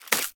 Sfx_creature_babypenguin_hop_03.ogg